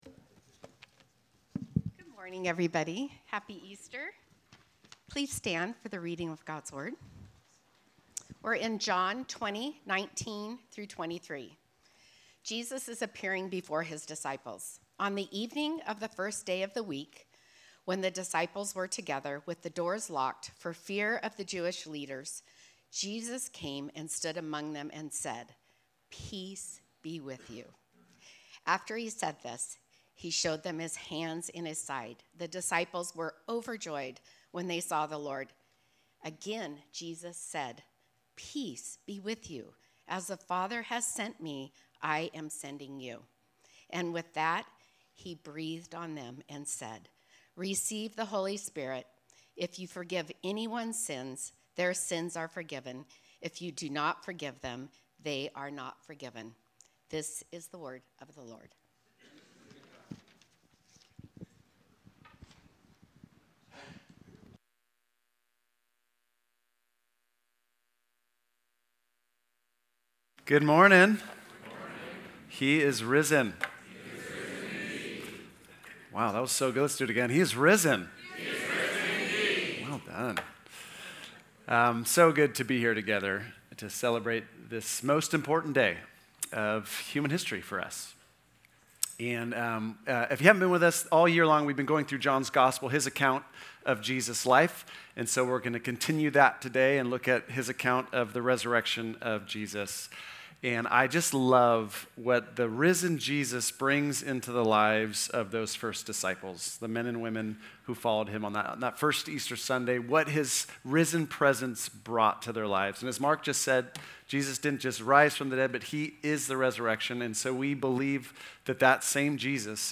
Sermon-4-4-26.mp3